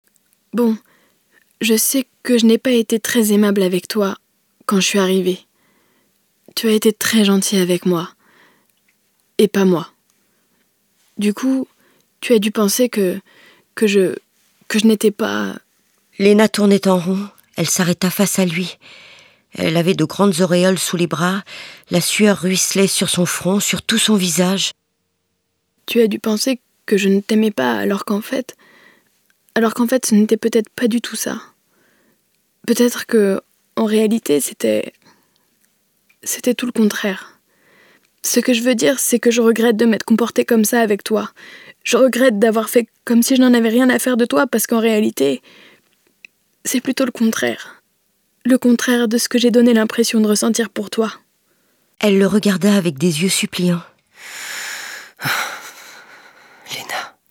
Voix reconnaissable typique modulable jeune posée dynamique
Sprechprobe: Sonstiges (Muttersprache):